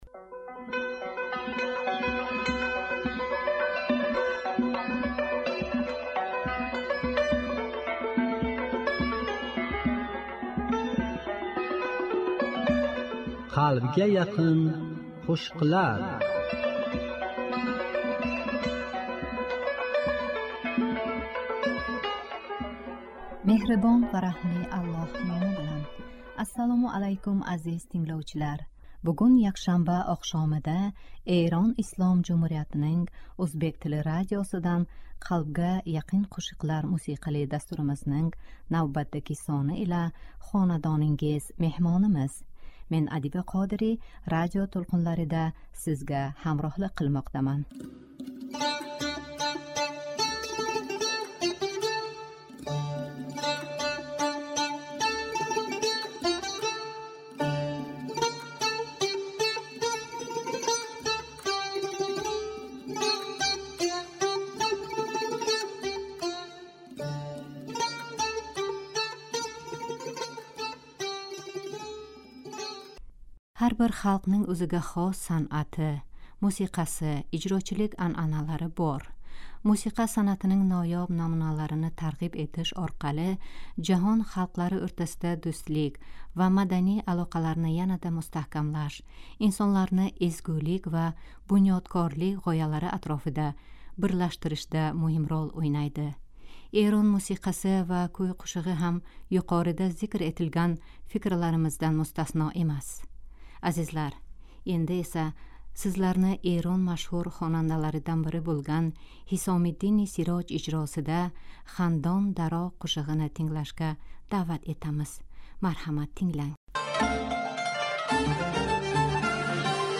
Азизлар, "Қалбга яқин қўшиқлар" дастуримизнинг бугунги сонида сиз азиз тингловчиларга тақдим этадиган форс,ўзбек, ва тожик тилларида ижро этилган дилрабо қўшиқлар ўрин олган.